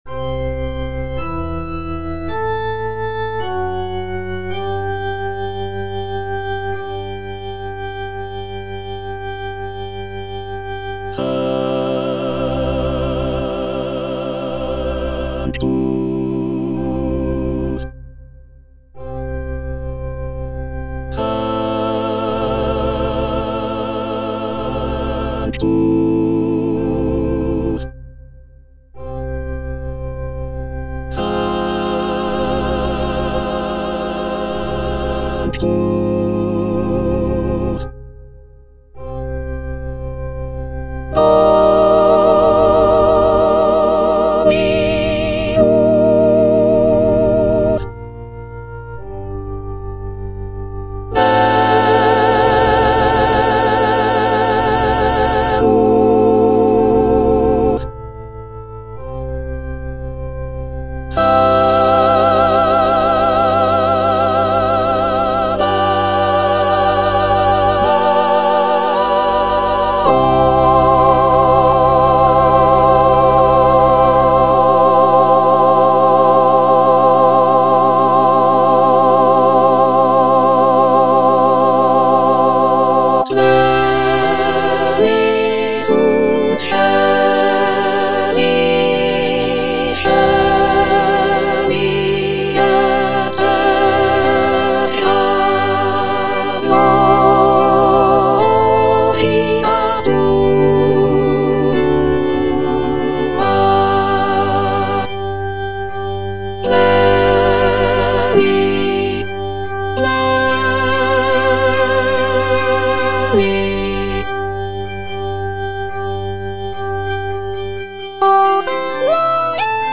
C.M. VON WEBER - MISSA SANCTA NO.1 Sanctus (soprano II) (Emphasised voice and other voices) Ads stop: auto-stop